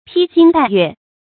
披星帶月 注音： ㄆㄧ ㄒㄧㄥ ㄉㄞˋ ㄩㄝˋ 讀音讀法： 意思解釋： 頂著星月奔走。形容早出晚歸或夜行。